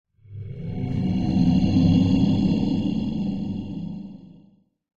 cave8.ogg